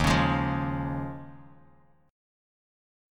D#9sus4 chord